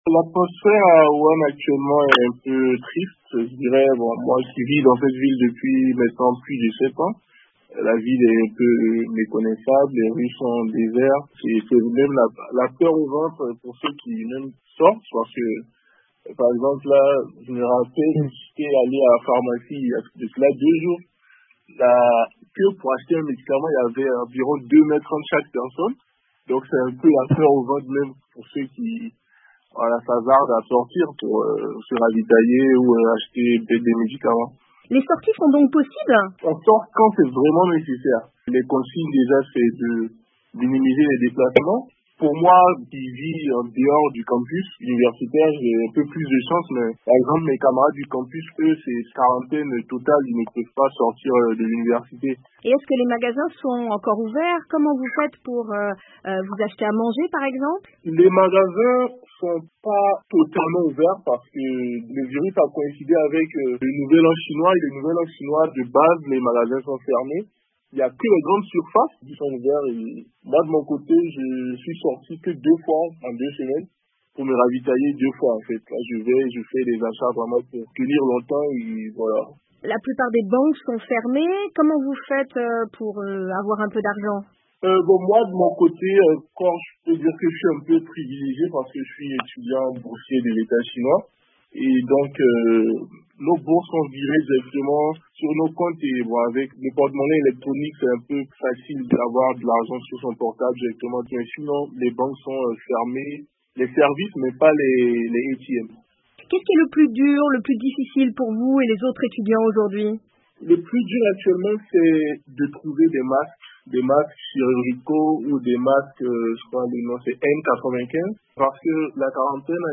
Coronavirus : un étudiant ivoirien témoigne sur les conditions de vie à Wuhan